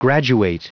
Prononciation du mot graduate en anglais (fichier audio)
Prononciation du mot : graduate